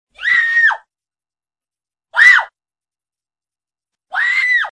Descarga de Sonidos mp3 Gratis: grito 8.